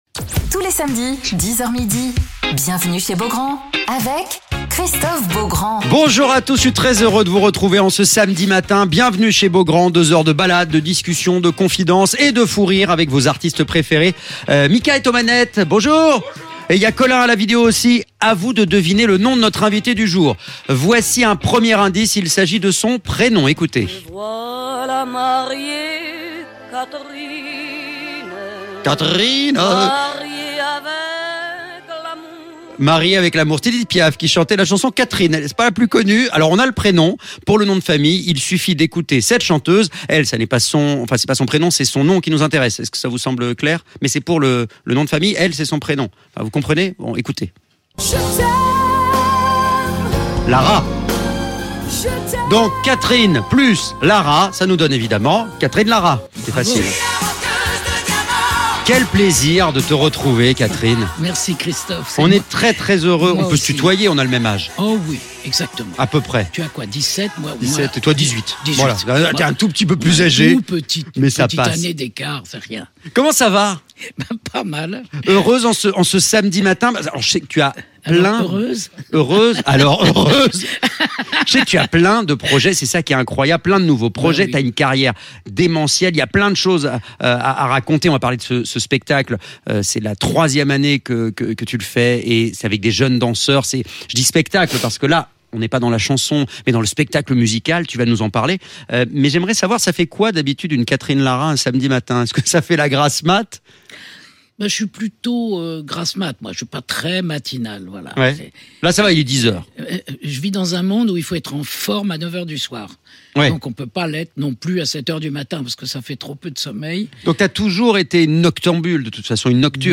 Alors qu'elle poursuit la tournée du spectacle "IDENTITES" avec la compagnie KUMO, Catherine Lara est l'invitée de Christophe Beaugrand sur M Radio !